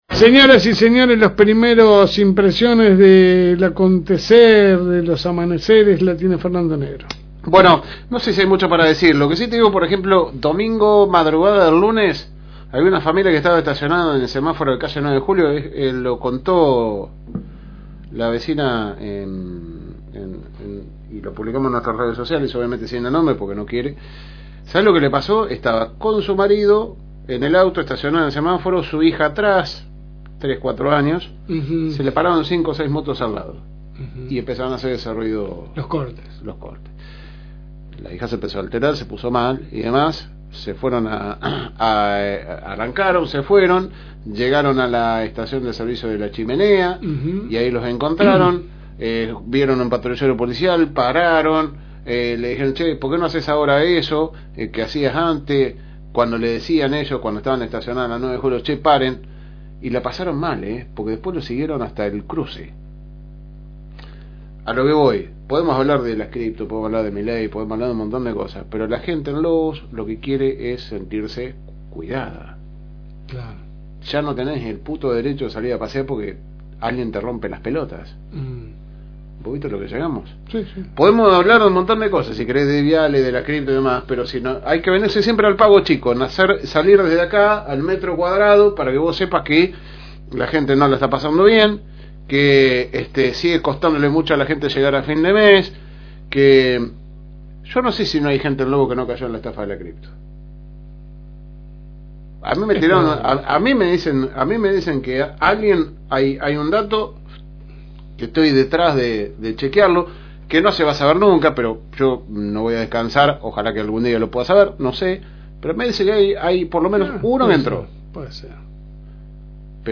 AUDIO – Editorial de LSM – FM Reencuentro